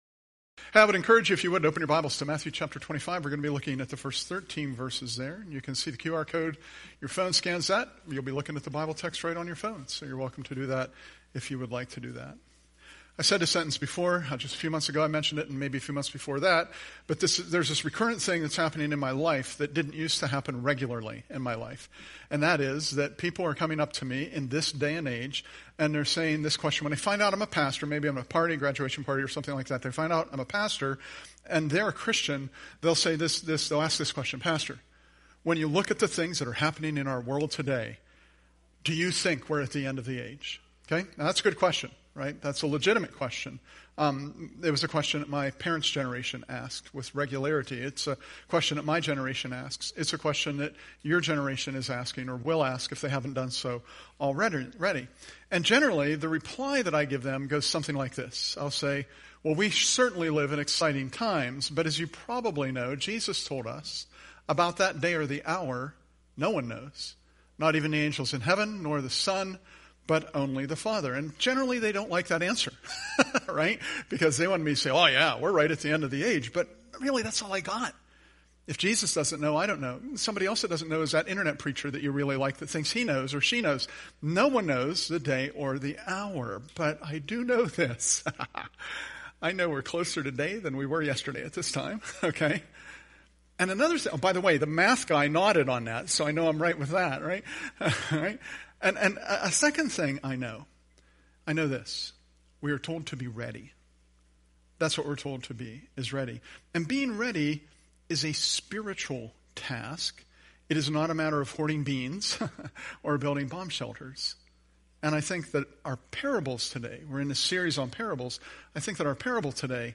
The Ten Virgins- The Pitfall of Neglect :: PARABLES – Curwensville Alliance Church Podcasts